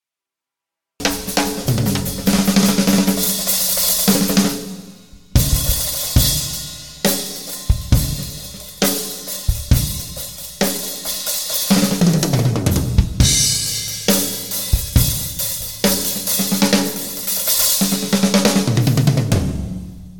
bateria.mp3